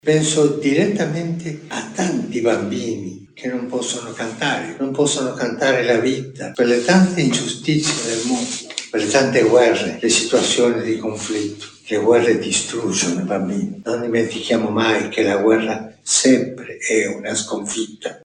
Avete appena ascoltato il messaggio di Papa Francesco nella serata inaugurale del Festival di Sanremo 2025.